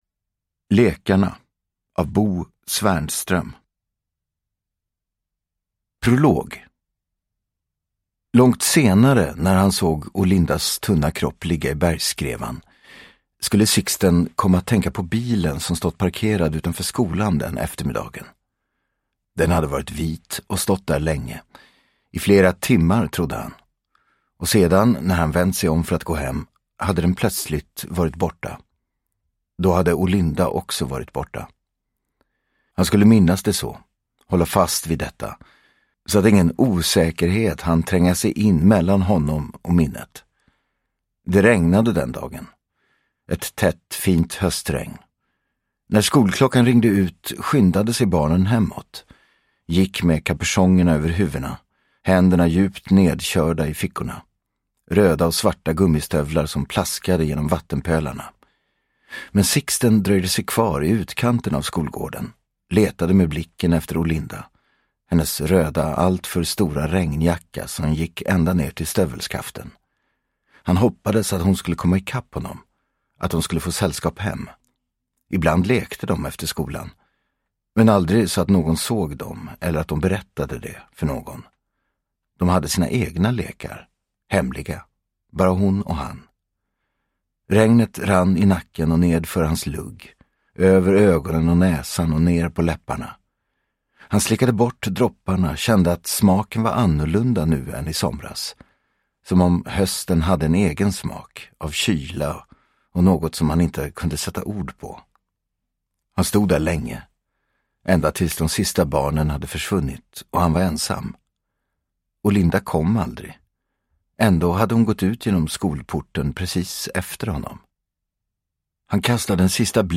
Lekarna (ljudbok) av Bo Svernström | Bokon